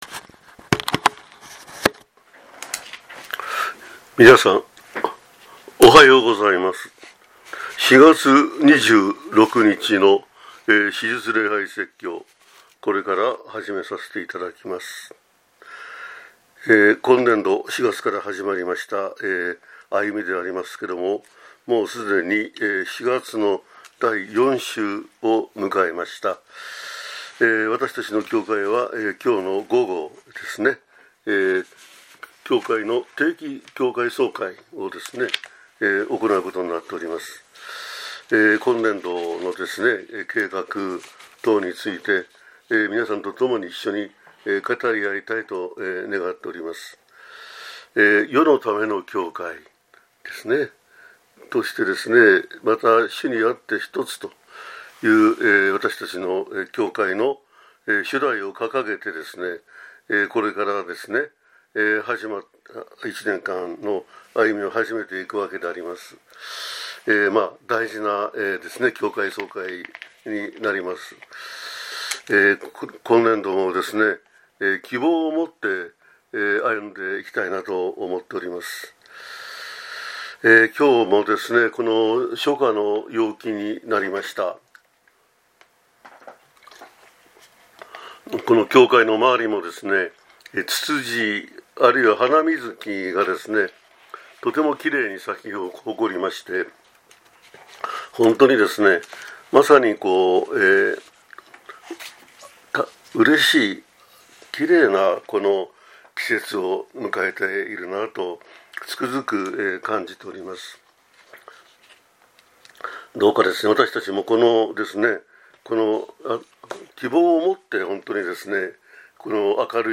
2026年4月26日（復活節第4主日）